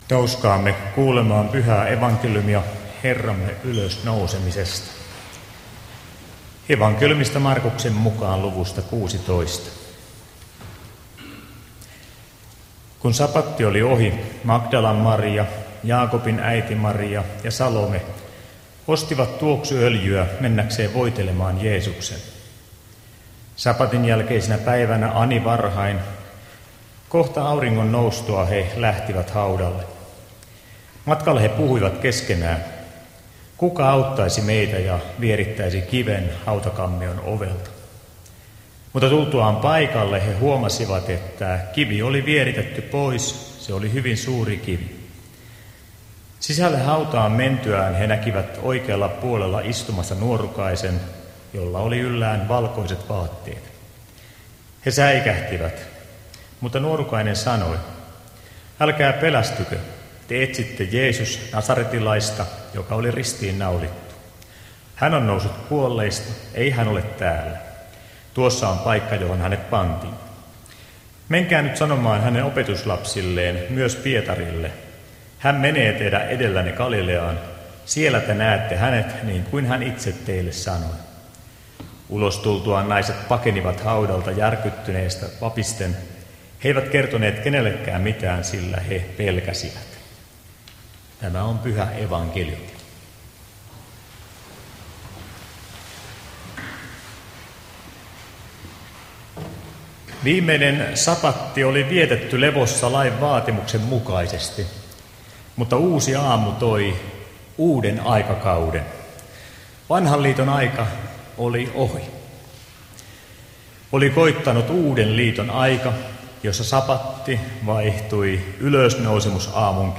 saarna